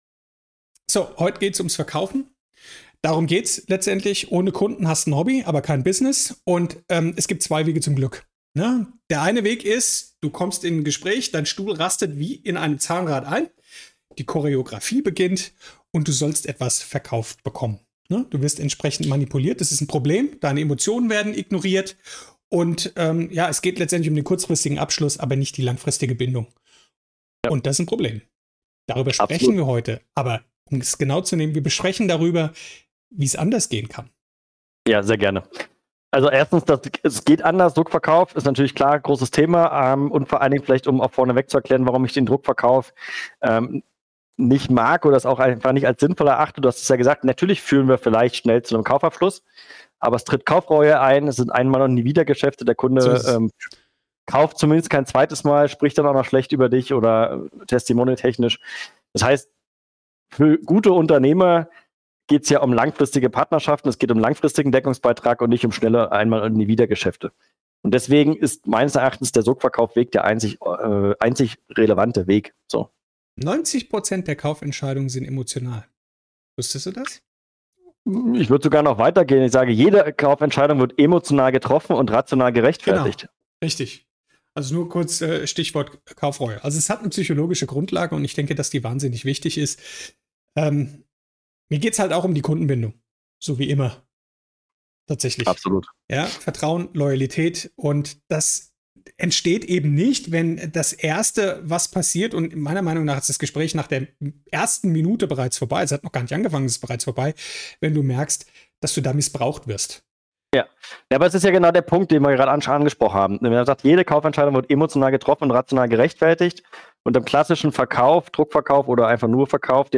Ein echtes Gespräch voller praktischer Tipps und wertvoller Einblicke!